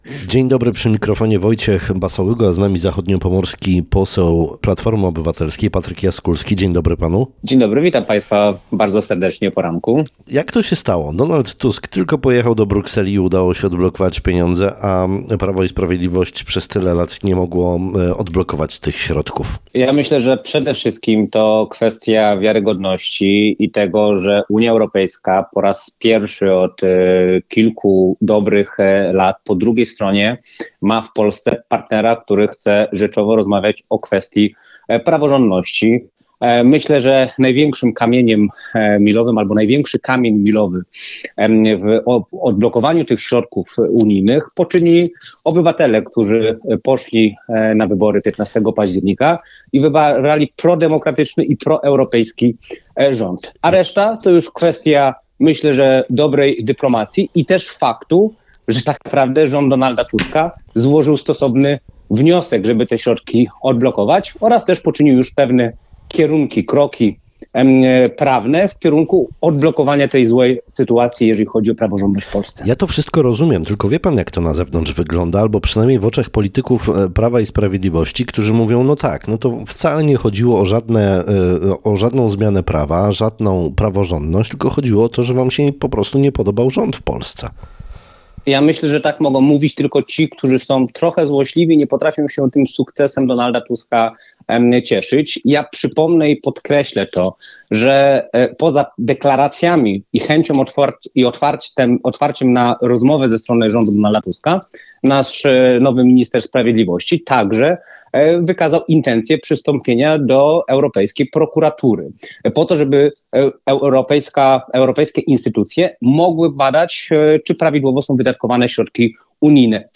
Zachodniopomorski poseł Patryk Jaskulski, który w poniedziałek był Gościem Twojego Radia twierdzi, że unia odzyskała zaufanie do Polski.